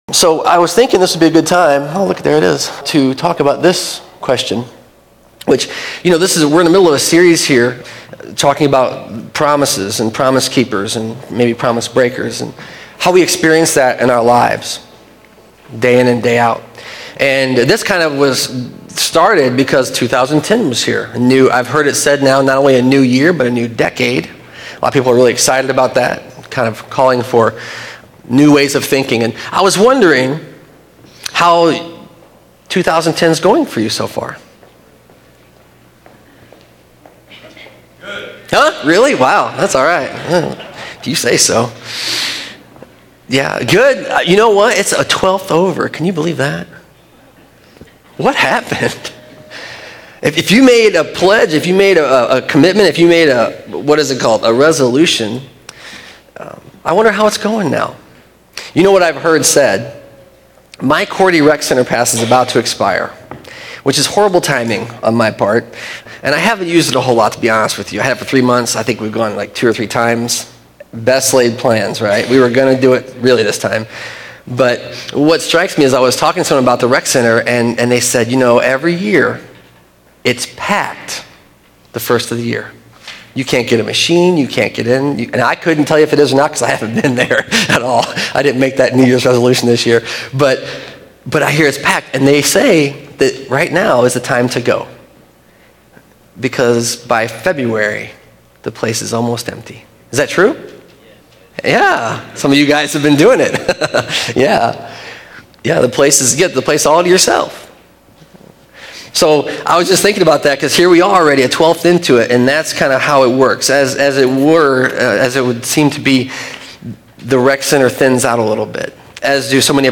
Discuss this Sermon Windows Media This sermon is available via Podcast December 24